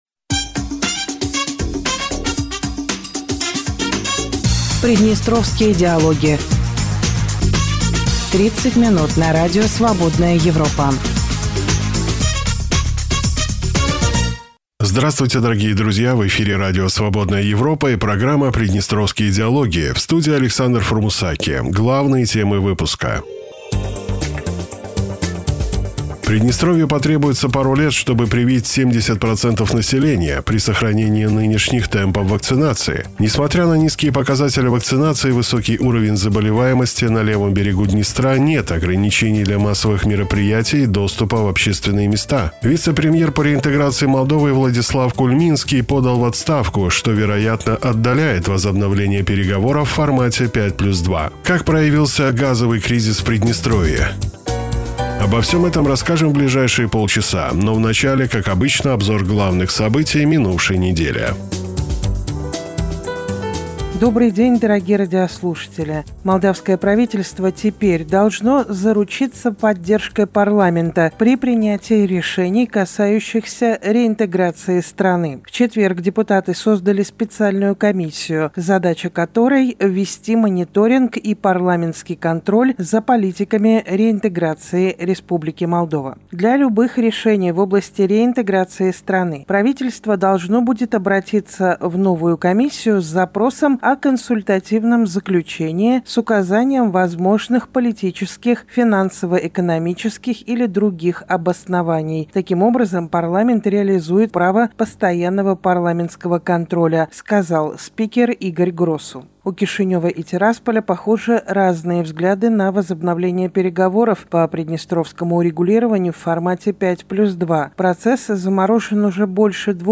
В эфире Радио Свободная Европа и программа Приднестровские диалоги.